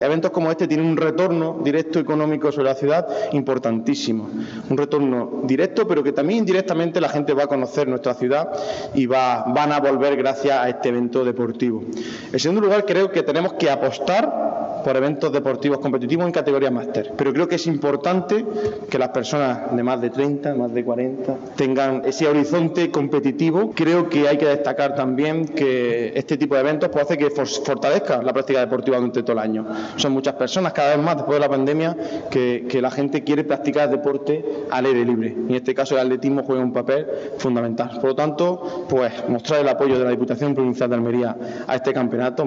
Esta mañana se ha presentado la cita nacional en la sala de prensa del Ayuntamiento de Almería
20-06_diputado_jose_a._garcia.mp3